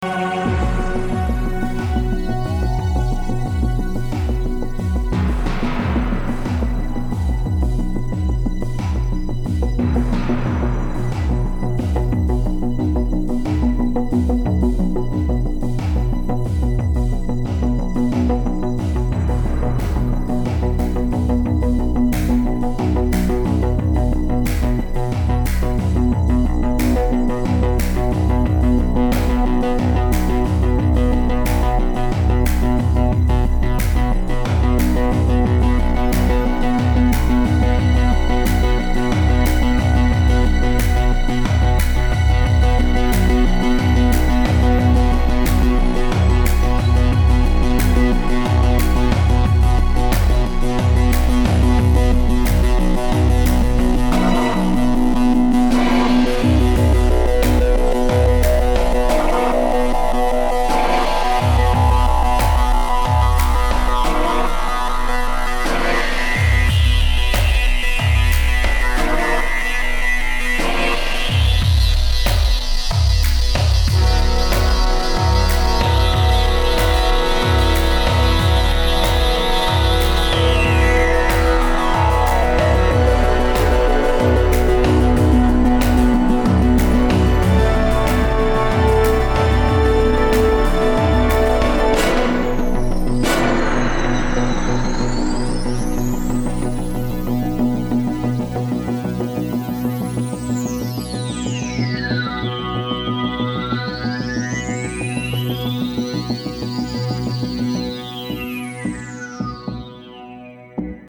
AAS Tassman, Arturia Moog Modular V, AudioNerdz Delay Lama, East West Quantum Leap Symphonic Orchestra Gold, Emu Proteus X, e-phonic RetroDelay, Garritan Personal Orchestra, Gmedia M-Tron, Kjaerhus Classic Effects, Knufinke SIR 1, Korg Legacy Collection MS 20, OdO Rhythms, Siedlaczek String Essentials, Steinberg Cubase SX, Virsyn Cube, Virsyn MicroTera.